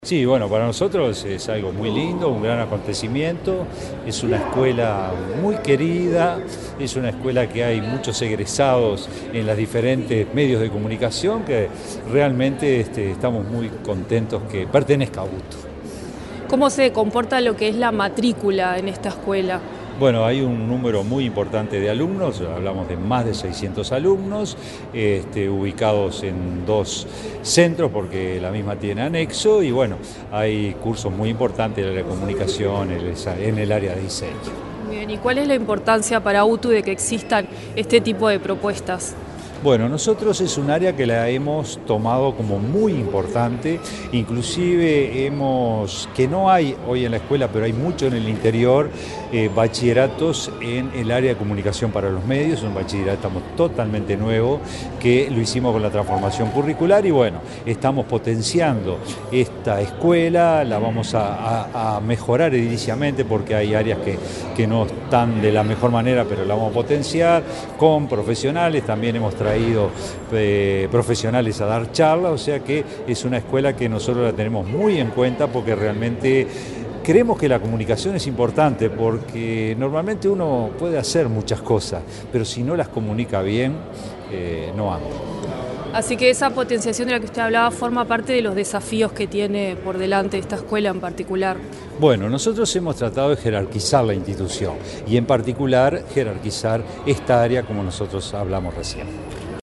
Entrevista al director general de UTU, Juan Pereyra
Entrevista al director general de UTU, Juan Pereyra 30/07/2024 Compartir Facebook X Copiar enlace WhatsApp LinkedIn El director general de Educación Técnico Profesional-UTU, Juan Pereyra, dialogó con Comunicación Presidencial, el lunes 29, durante la celebración de los 45 años de la Escuela Superior de Comunicación Social y Diseño Grafico de la referida institución.